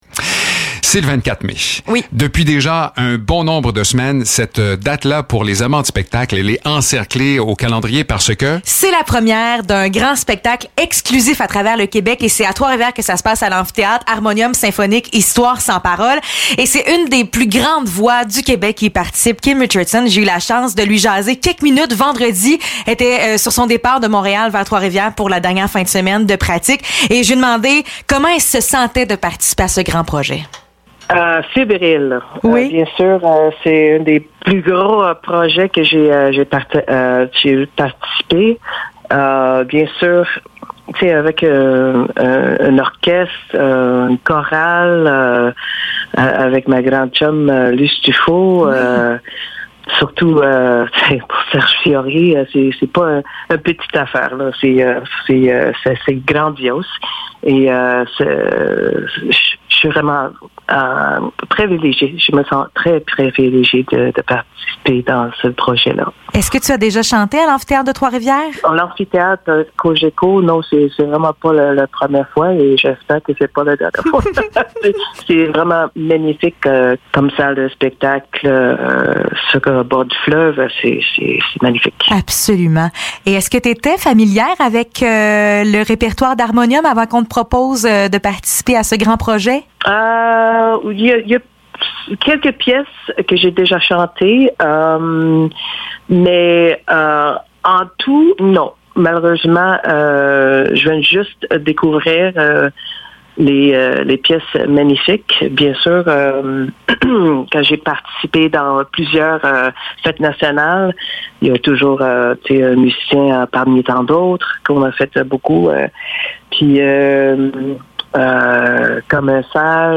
Entrevue avec Kim Richardson (24 mai 2022)